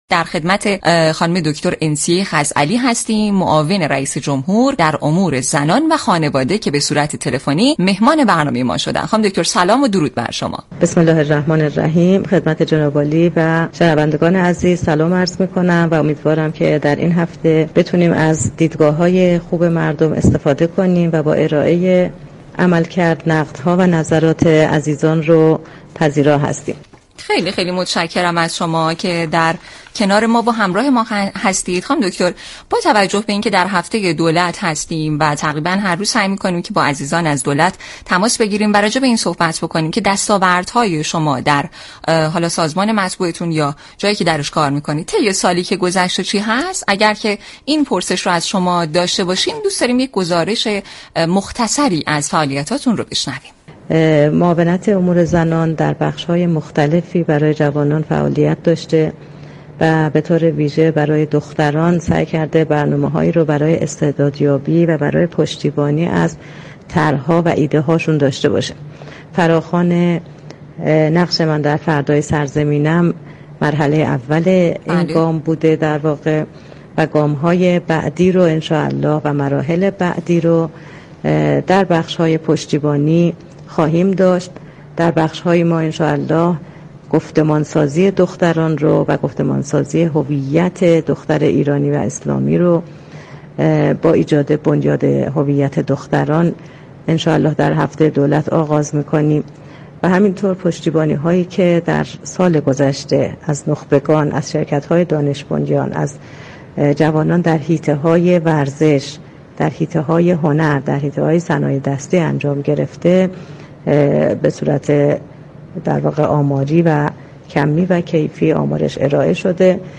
انسیه خزعلی، معاون رئیس جمهور در امور زنان و خانواده در گفت‌وگو با رادیو جوان در برنامه «خط آزاد» گفت: معاونت امور زنان در بخش‌های مختلف برای جوانان فعالیت داشته، به‌طور ویژه برای دختران برنامه استعدادیابی و پشتیبانی از طرح و ایده‌ها را درنظر گرفته است.